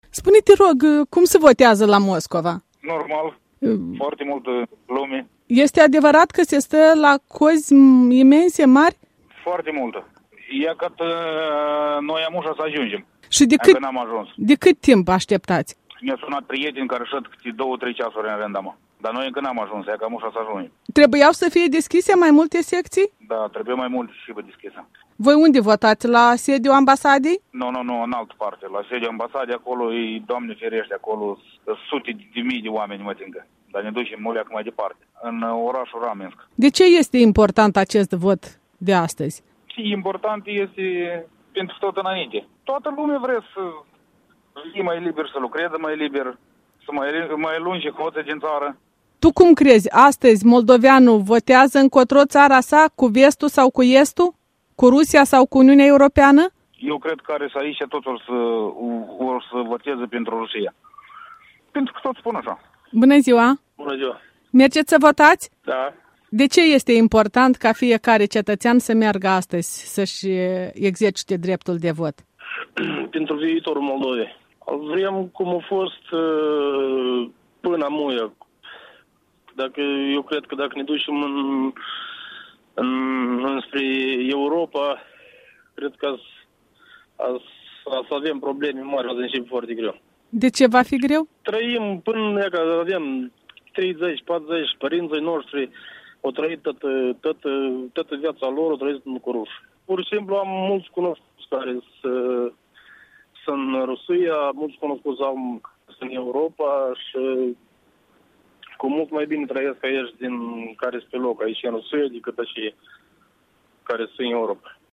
Europa Liberă de vorbă cu doi alegători moldoveni din capitala rusă